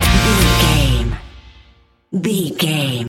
Ionian/Major
E♭
acoustic guitar
electric guitar
drums
bass guitar